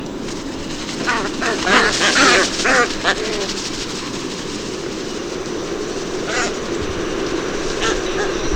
White Ibis
Eudocimus albus